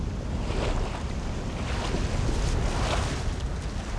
WAV · 172 KB · 單聲道 (1ch)